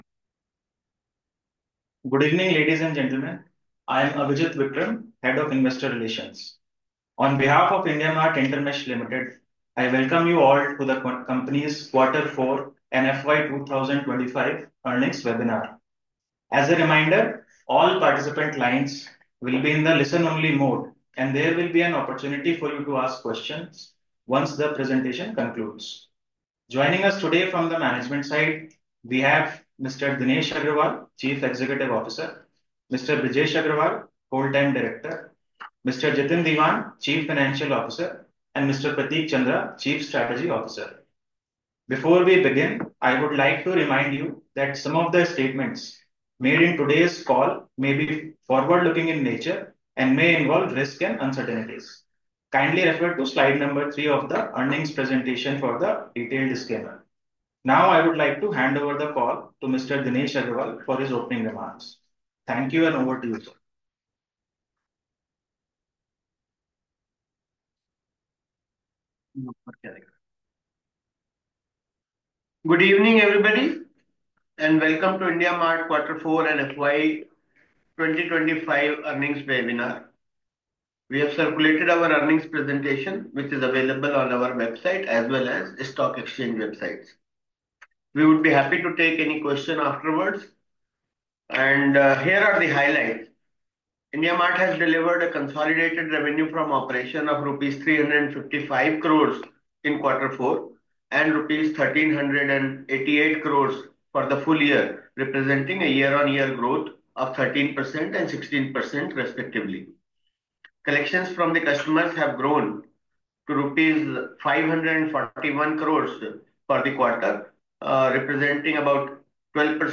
IndiaMART_Q4_FY25_Earnings_Webinar_Audio_Recording.mp3